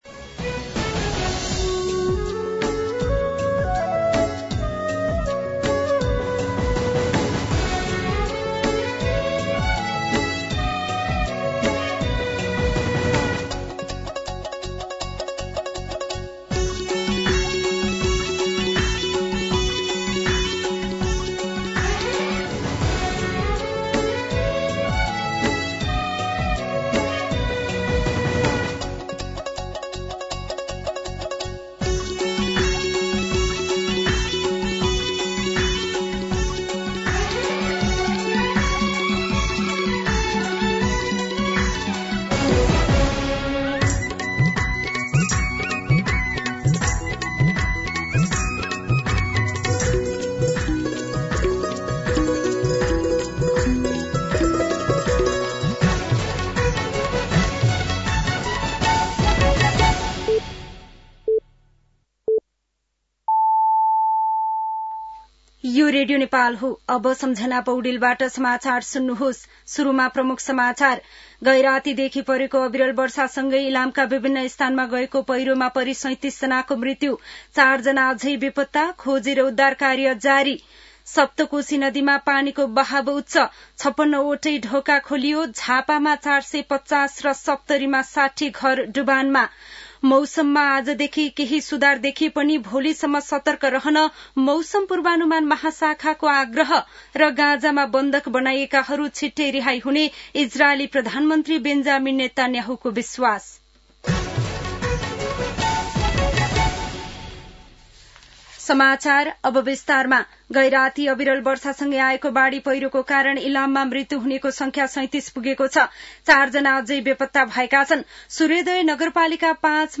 दिउँसो ३ बजेको नेपाली समाचार : १९ असोज , २०८२
3-pm-Nepali-News-2.mp3